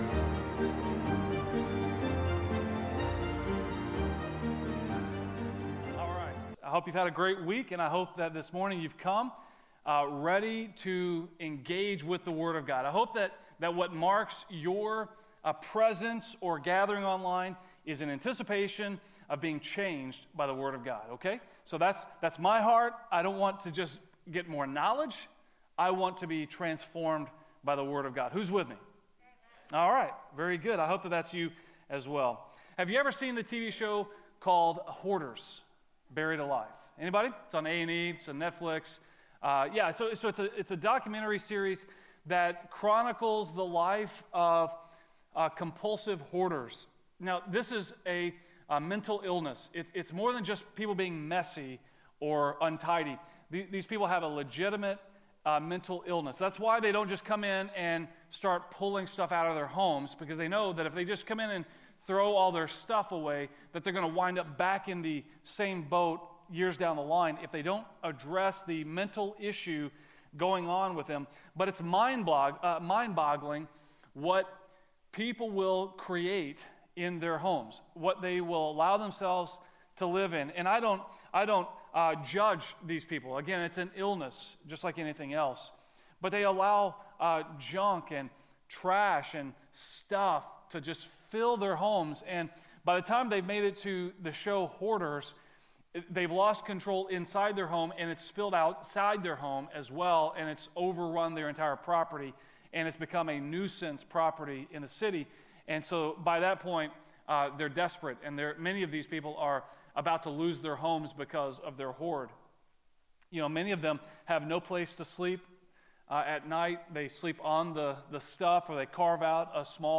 A message from the series "Advent 2020."